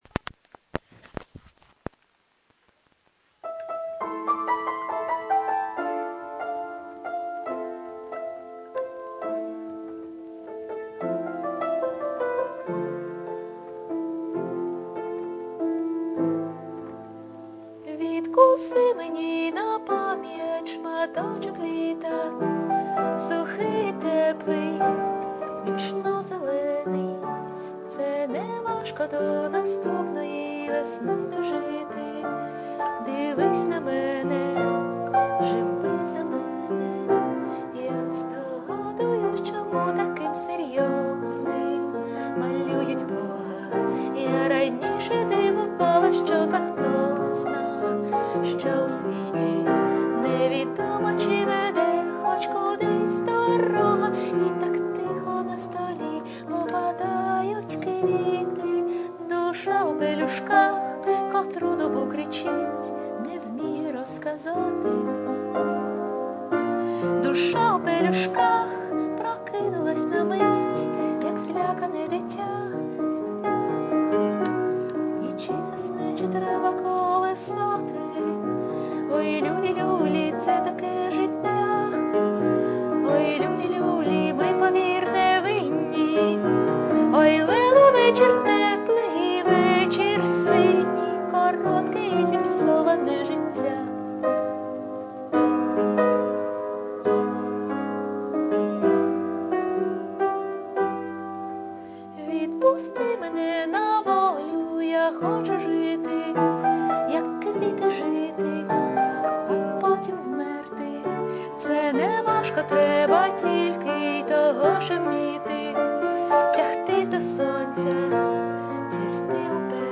Приємний голос!